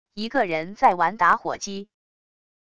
一个人在玩打火机wav音频